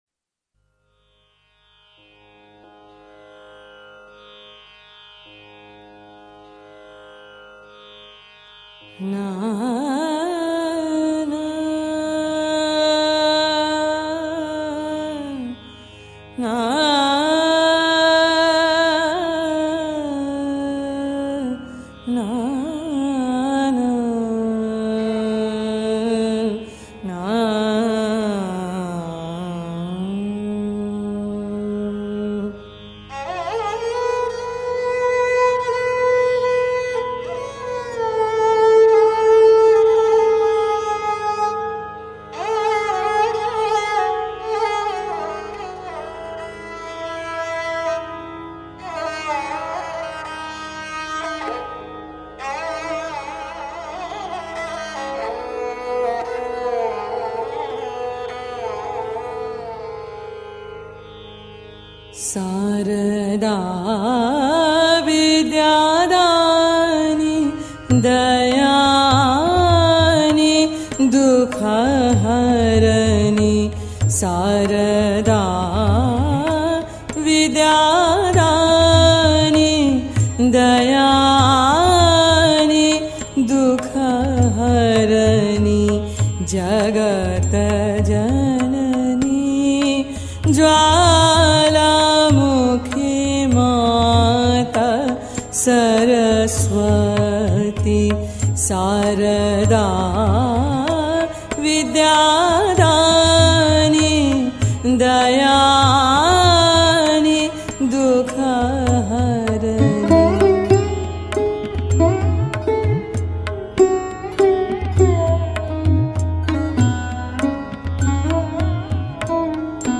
মূলগান: সারদা বিদ্যাদেনী। [ভৈরবী। ঝাঁপতাল]
Sarda Bidyadani (kheyal).mp3